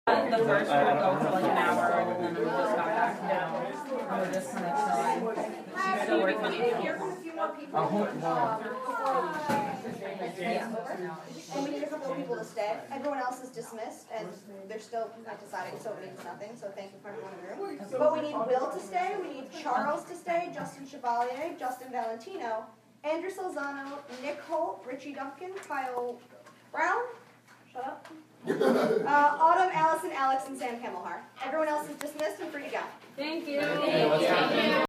Field Recording
People talking, announcements, footsteps
Auditions.mp3